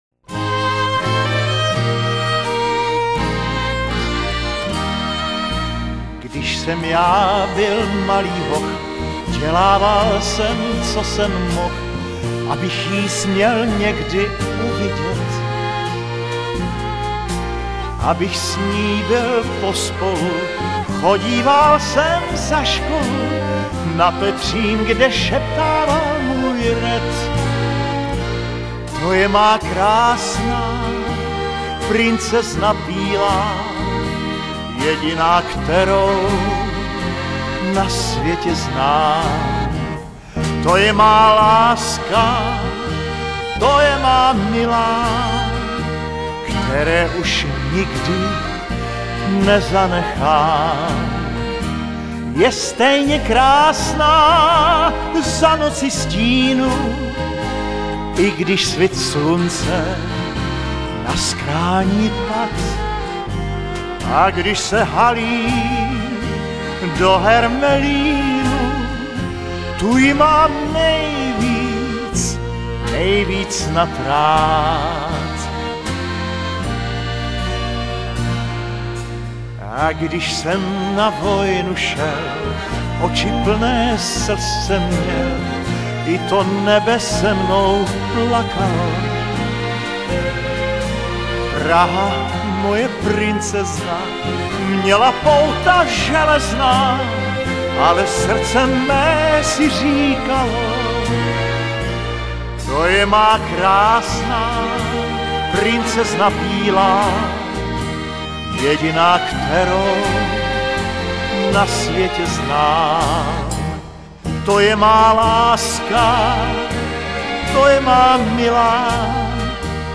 hrají a zpívají
Bertramka Session, přemícháno